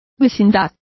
Complete with pronunciation of the translation of neighborhood.